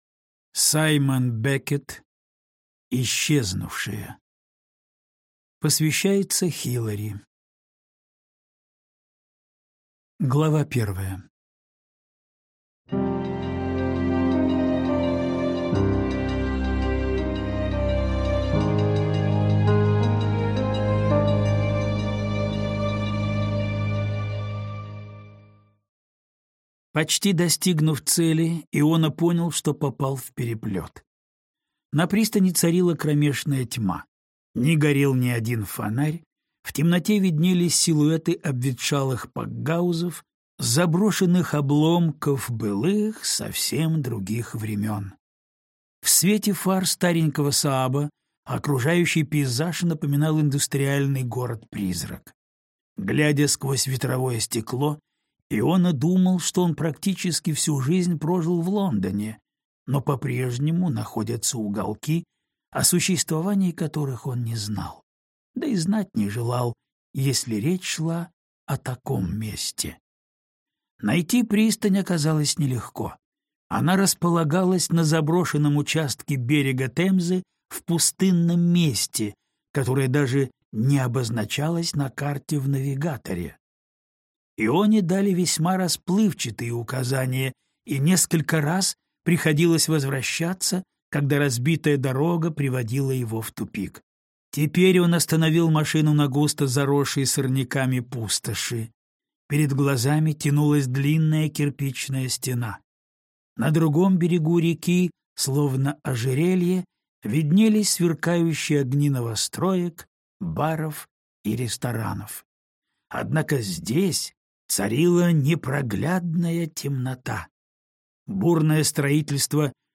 Аудиокнига Исчезнувшие | Библиотека аудиокниг
Прослушать и бесплатно скачать фрагмент аудиокниги